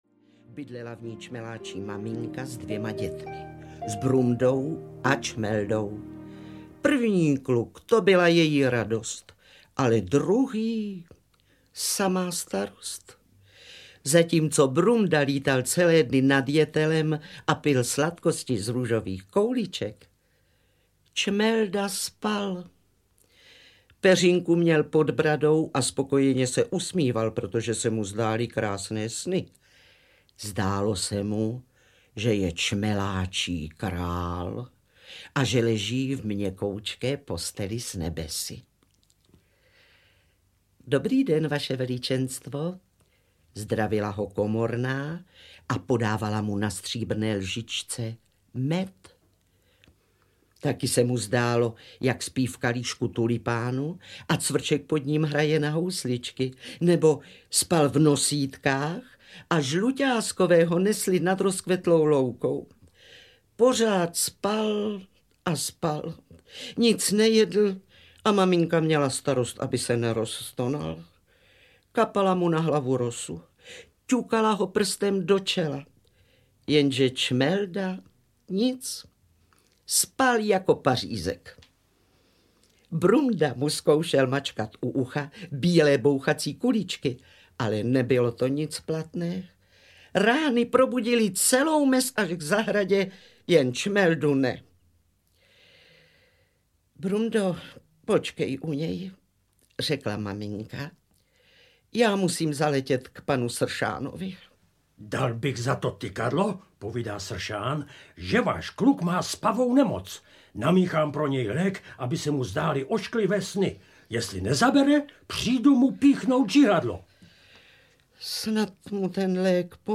Příhody včelích medvídků audiokniha
Ukázka z knihy
Účinkují Jitka Molavcová, Antonie Hegerlíková, Jana Boušková a Lubomír Lipský.